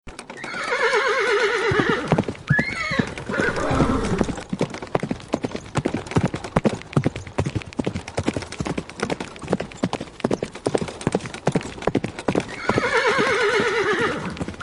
Horse Running Hq Bouton sonore